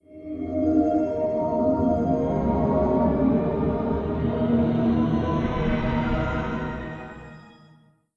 OS3 Warp 4.0 Startup.wav